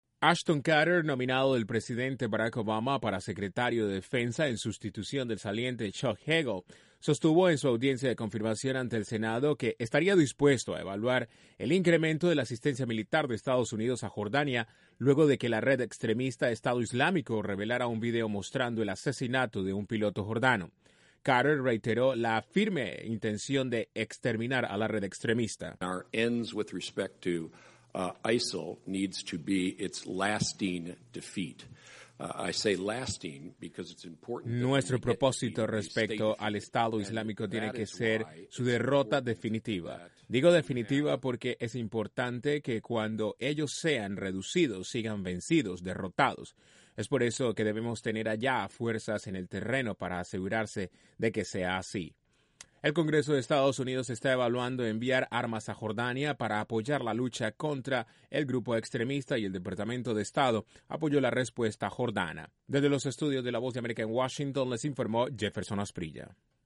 El nominado para jefe del Pentágono no descartó ante el Congreso a incrementar la ayuda militar de EEUU a Jordania para luchar contra el autodenominado Estado Islámico. Desde la Voz de América en Washington informa